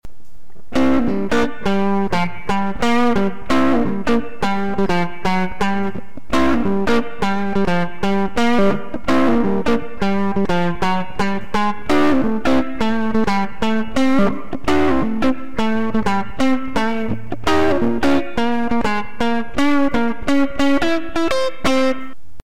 The following are mp3 samples that showcase different settings of Danstortion.
Guitar
Guitar_pos_100.mp3